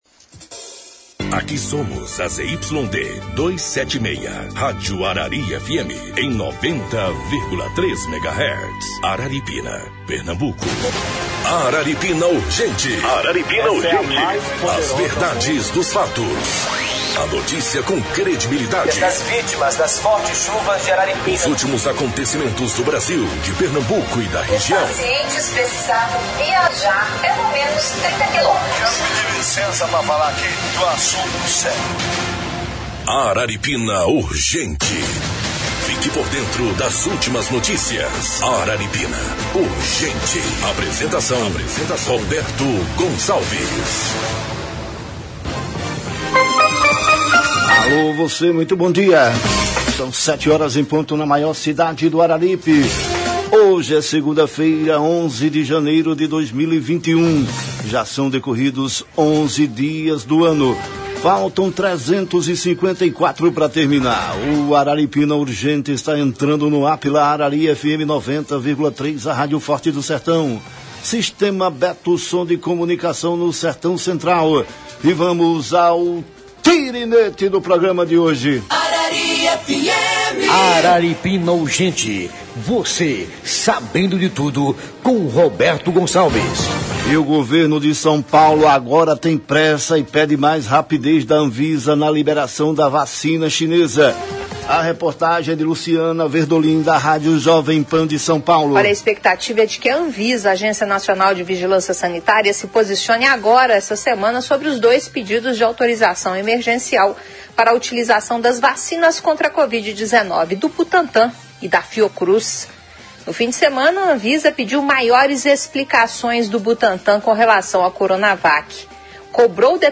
01-Entrevista